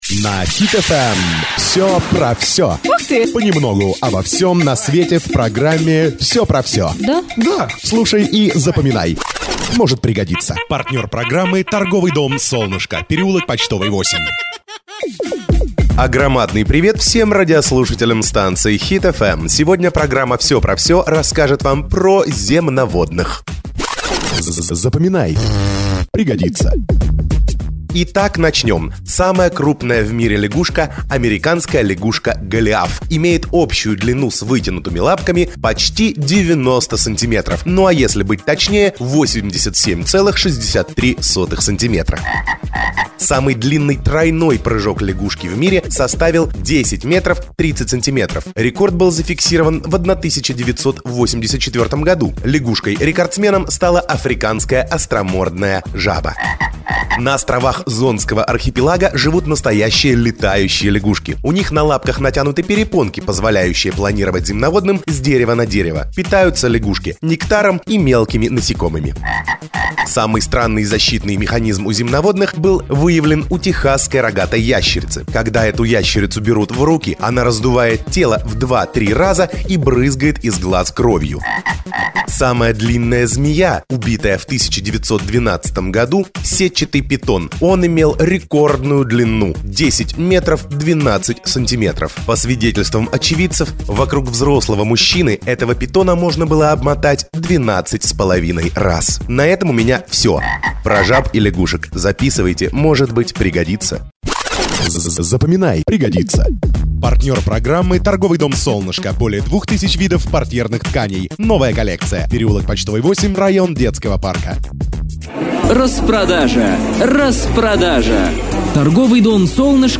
Продолжительность программы 2 минуты, в начале программы информация о Спонсоре, в конце программы рекламный аудиоролик.